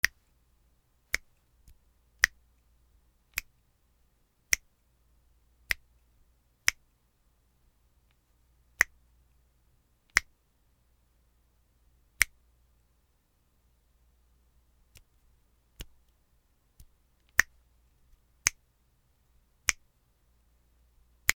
/ G｜音を出すもの / G-50 その他 手をたたく　体
指を鳴らす